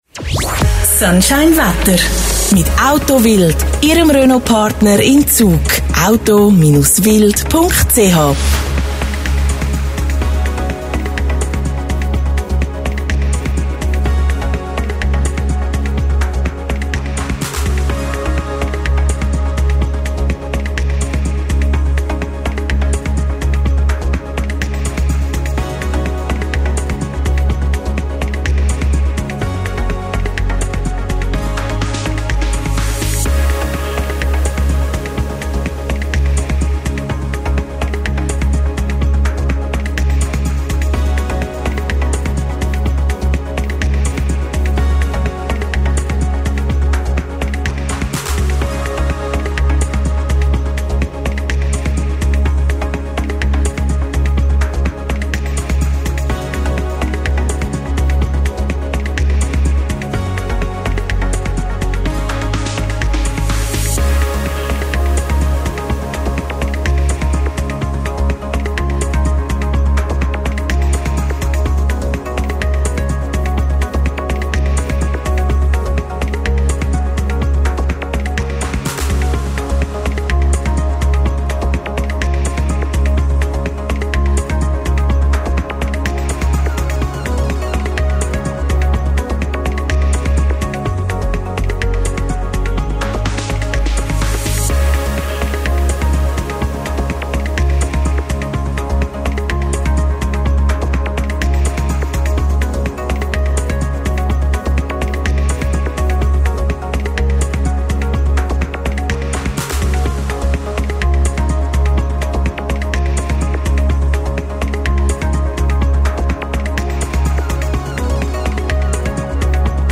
Sponsoring Wetter
Sunshine Wettersponsoring.mp3